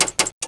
relay3.wav